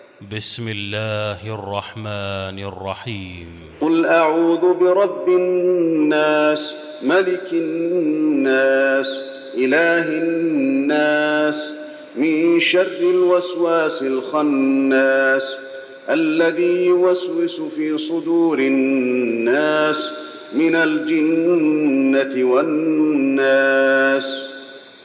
المكان: المسجد النبوي الناس